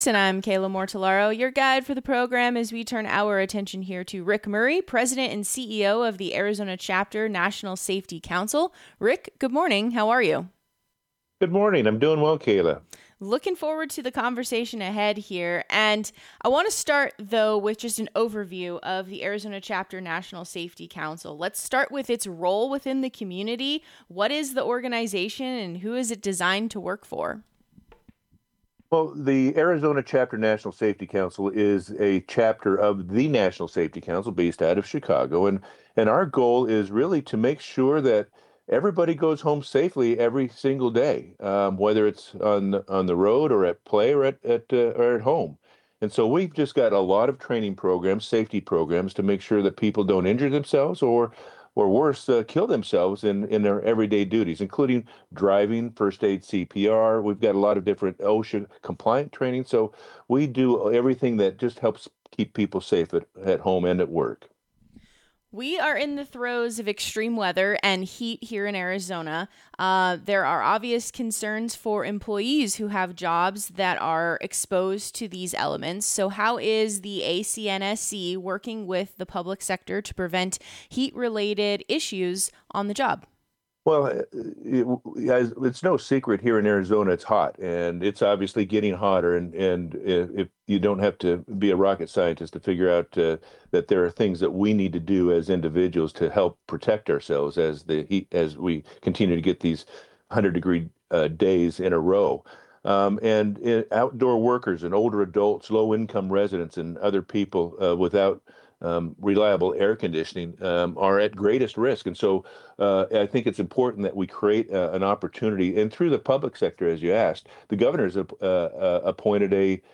Valley Focus radio interview